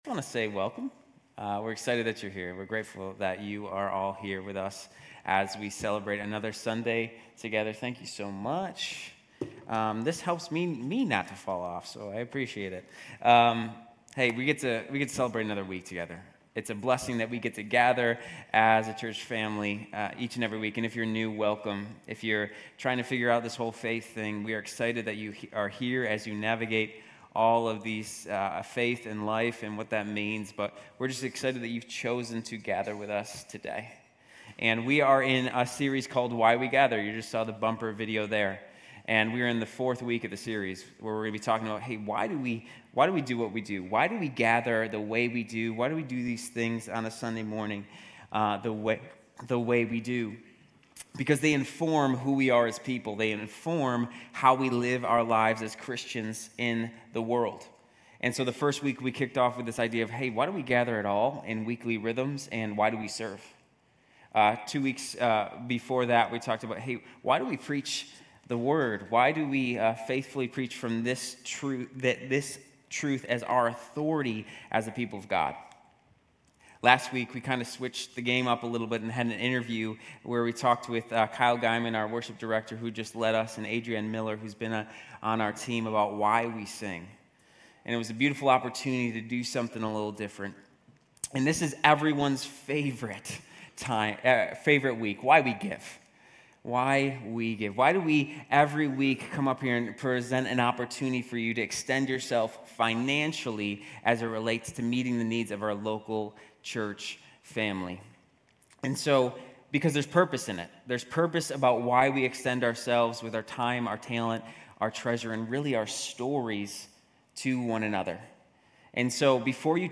GCC-UB-September-17-Sermon.mp3